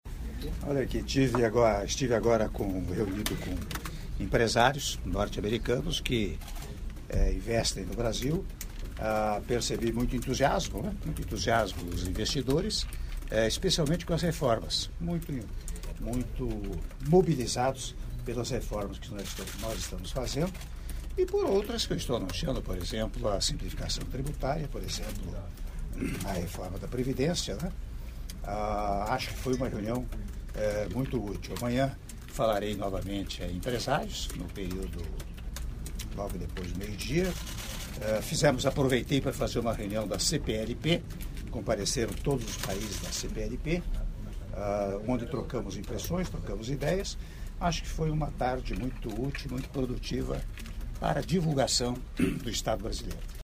Áudio da declaração à imprensa do Presidente da República, Michel Temer, após encontro com investidores, organizado pelo Conselho das Américas, em Nova York - Nova York/EUA (53s)